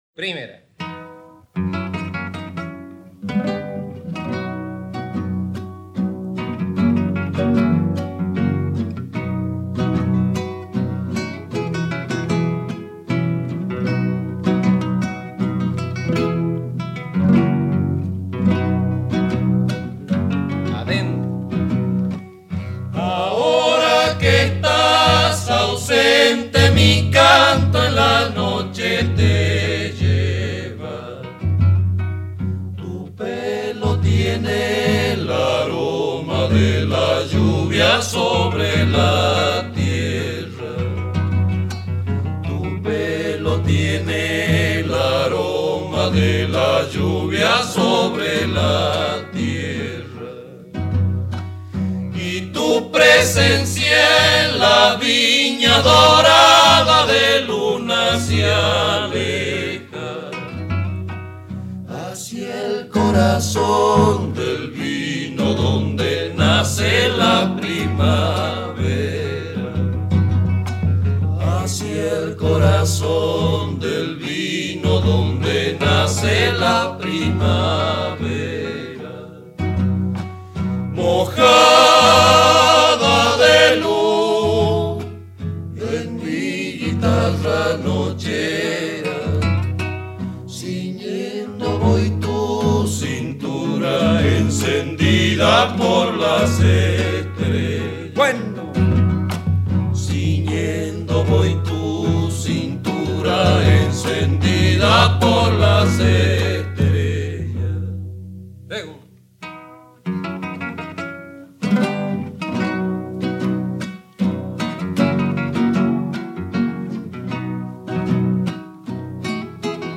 Folclore Argentino